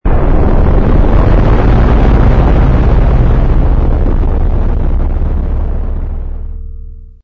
REM-Mod/DATA/AUDIO/SOUNDS/ENGINES/rumble_launch.wav at fd029501f7b0bc2a91f9a39bcbad752b661ef508
rumble_launch.wav